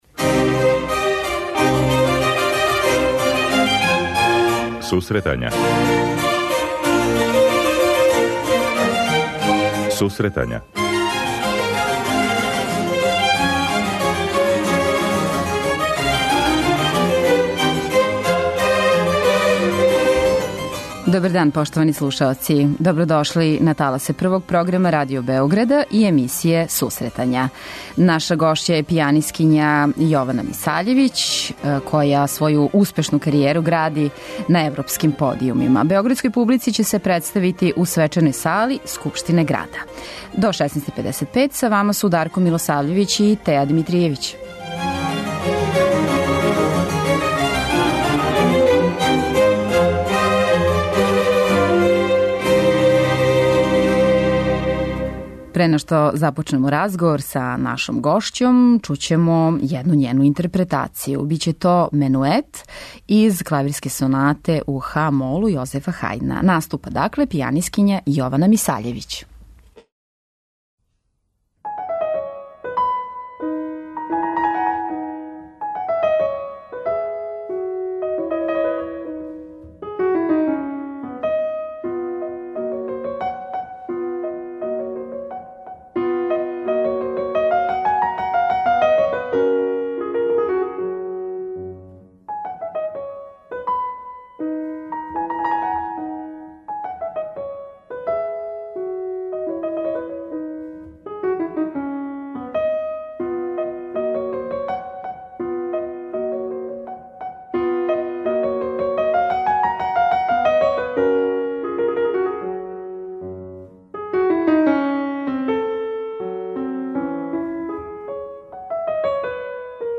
преузми : 26.18 MB Сусретања Autor: Музичка редакција Емисија за оне који воле уметничку музику.